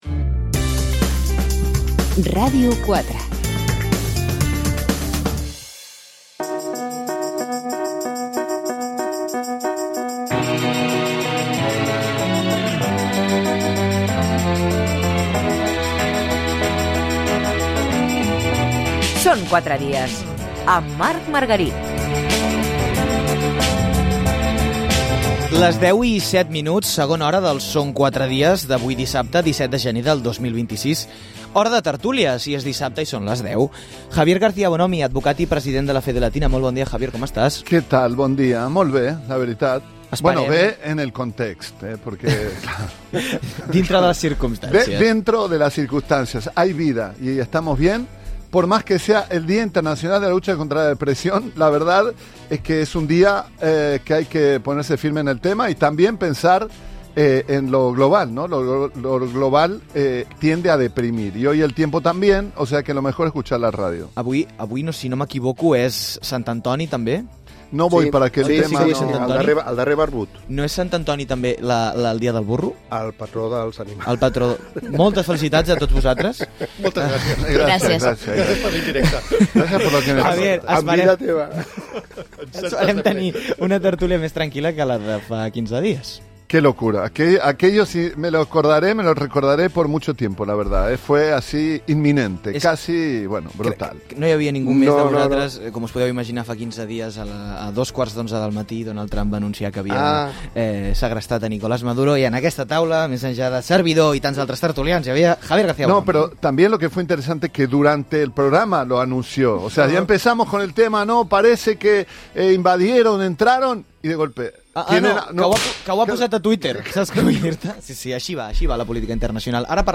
Tertúlia al Són 4 dies de Ràdio 4